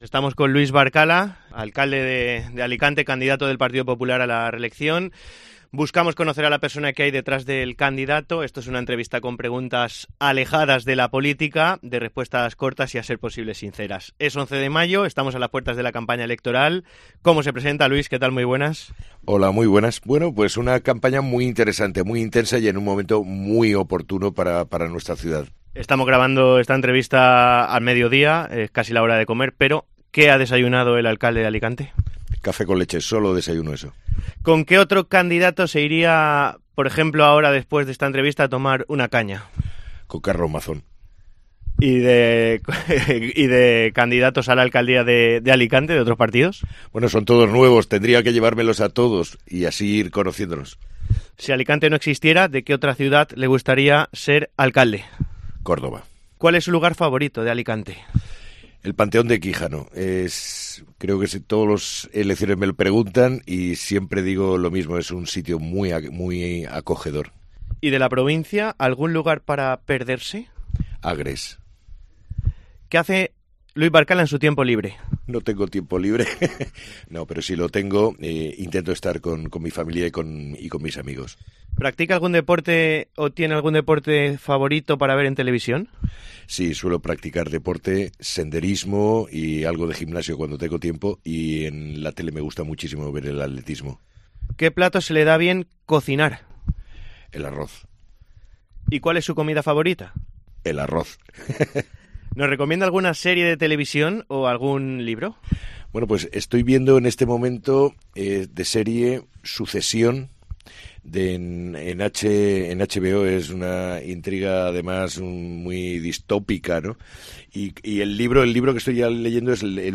Entrevista personal a Luis Barcala, candidato del PP a la Alcaldía de Alicante